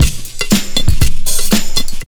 C2HOUSE120.wav